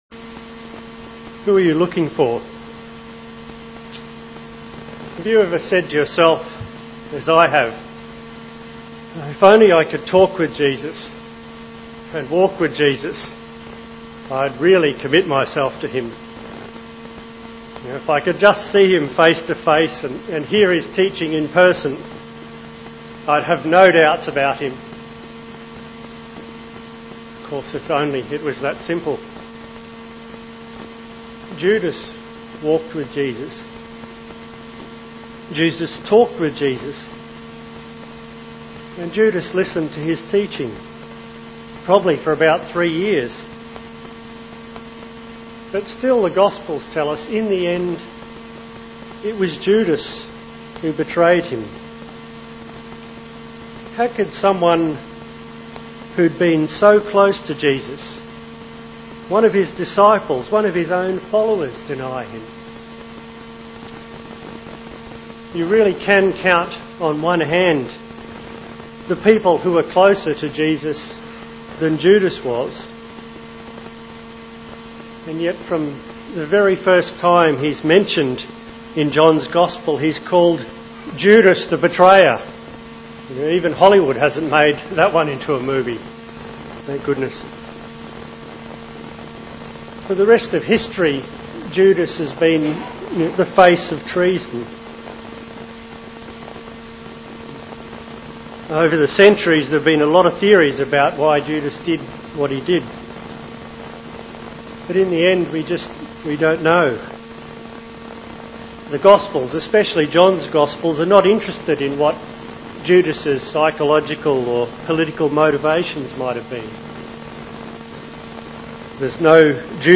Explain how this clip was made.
Good Friday Service of Meditations and Prayers